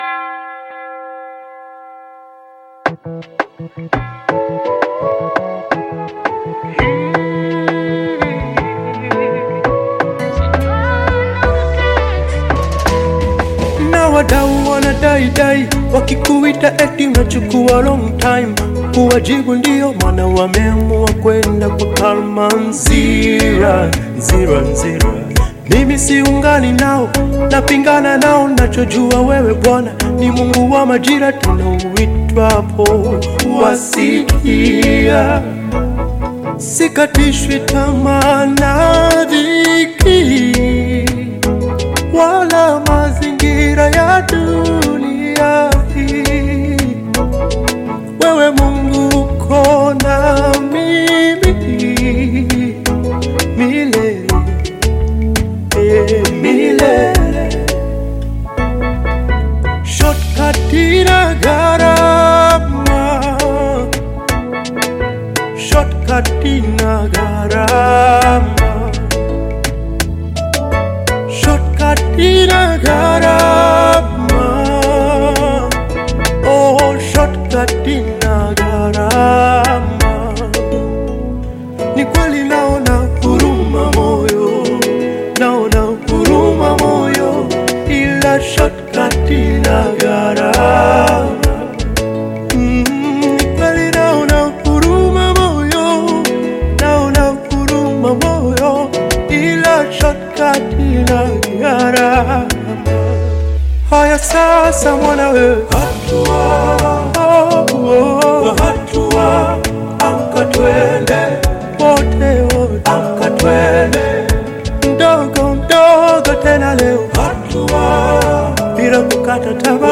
Gospel music track
Gospel song